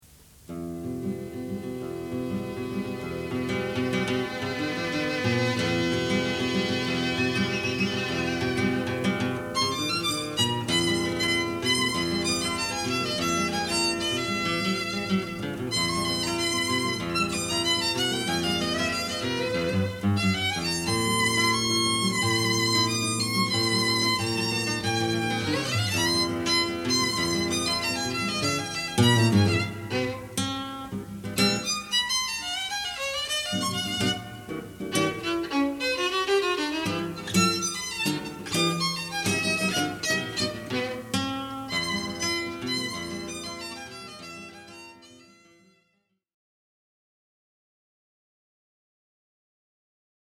Musikalische Saitenwege für Violine und Gitarre
Violine
Gitarre